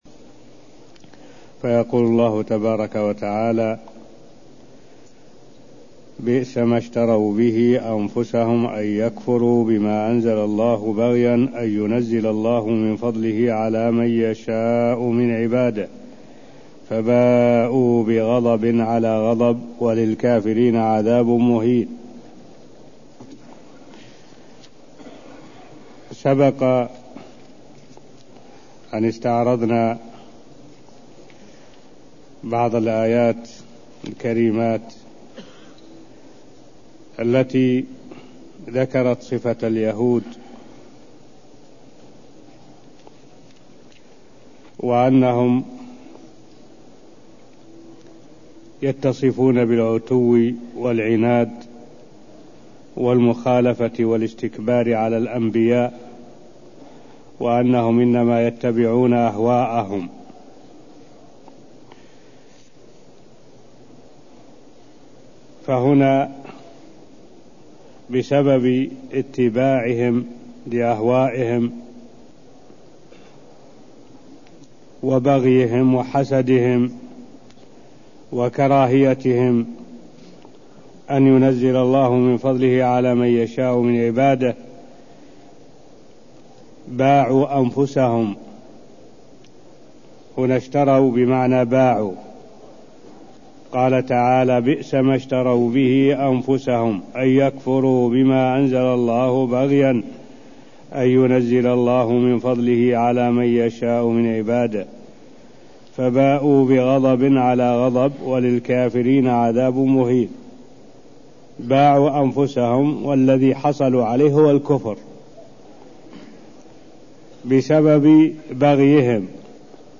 المكان: المسجد النبوي الشيخ: معالي الشيخ الدكتور صالح بن عبد الله العبود معالي الشيخ الدكتور صالح بن عبد الله العبود تفسير آيات من سورة البقرة (0052)